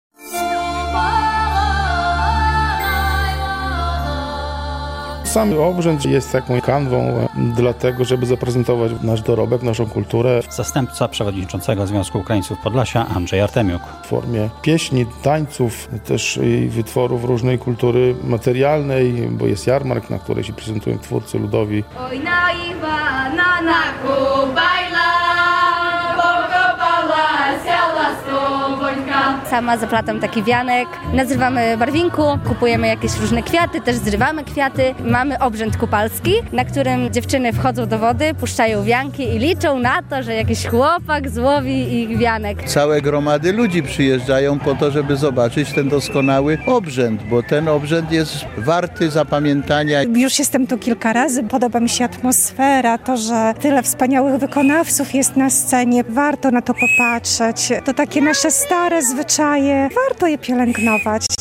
"Na Iwana, na Kupała" - magiczny wieczór w Dubiczach Cerkiewnych [zdjęcia]